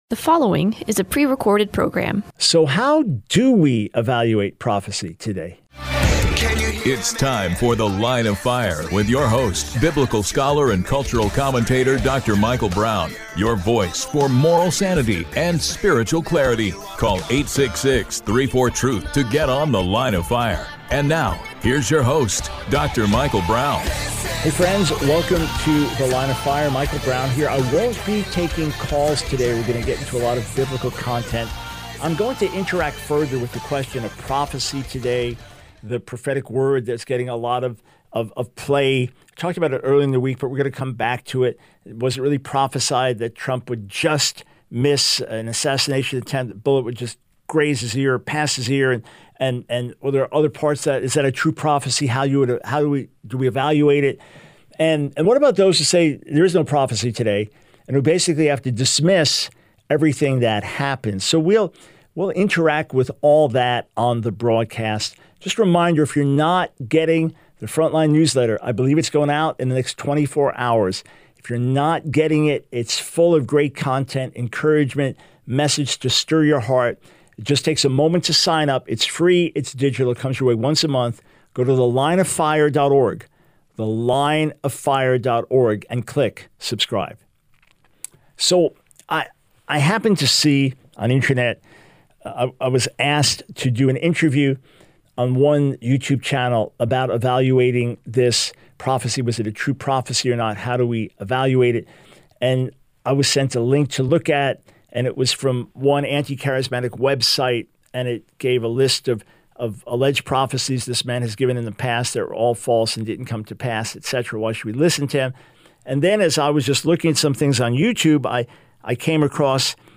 The Line of Fire Radio Broadcast for 07/17/24.